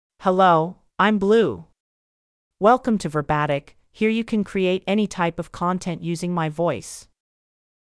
BlueFemale English AI voice
Blue is a female AI voice for English (United States).
Voice sample
Listen to Blue's female English voice.
Blue delivers clear pronunciation with authentic United States English intonation, making your content sound professionally produced.